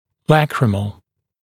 [‘lækrɪml][‘лэкримл]слезный, лакримальный